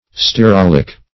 Search Result for " stearolic" : The Collaborative International Dictionary of English v.0.48: Stearolic \Ste`a*rol"ic\ (-r[o^]l"[i^]k), a. [Stearic + oleic + -ic.]